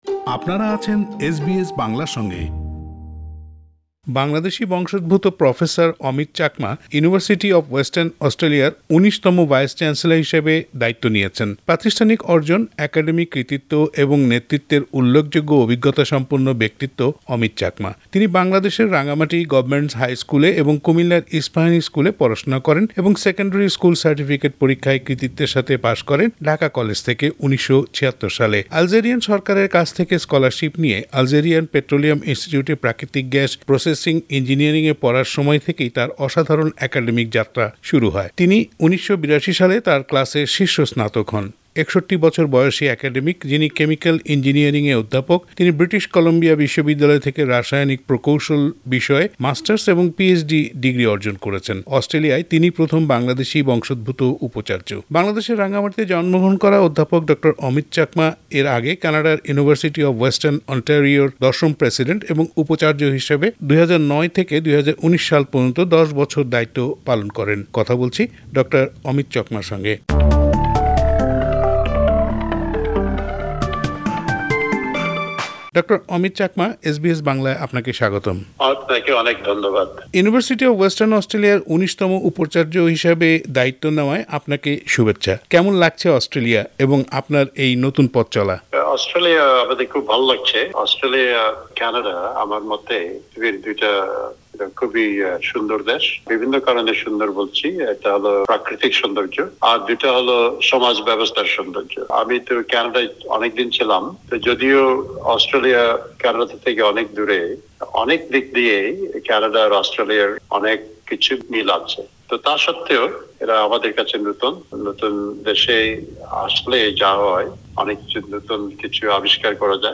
বাংলাদেশি বংশোদ্ভূত প্রফেসর ডঃ অমিত চাকমা ইউনিভার্সিটি অফ ওয়েস্টার্ন অস্ট্রেলিয়ার ১৯তম ভাইস চ্যান্সেলর হিসাবে দায়িত্ব নিয়েছেন। প্রাতিষ্ঠানিক অর্জন, একাডেমিক কৃতিত্ব এবং নেতৃত্বের উল্লেখযোগ্য অভিজ্ঞতা সম্পন্ন ব্যক্তিত্ব ডঃ অমিত চাকমার সাথে শুনুন একটি আলাপচারীতা।